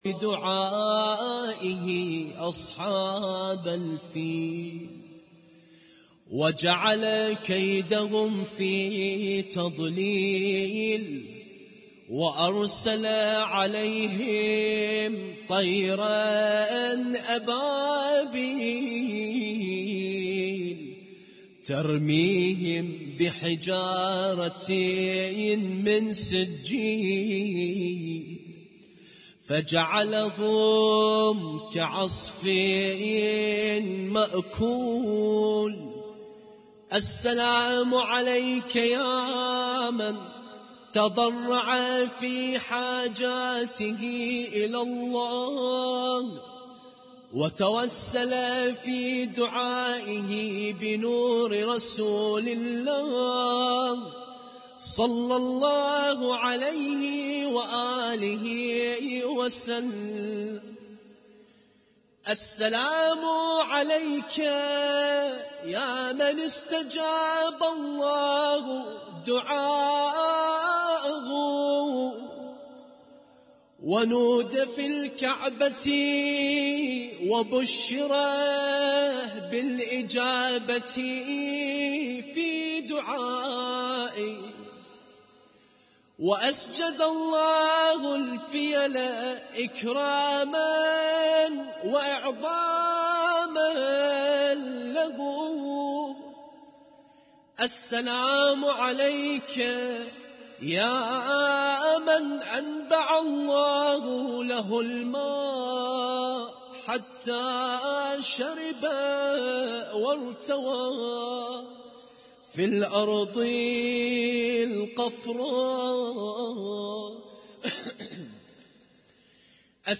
القارئ: الرادود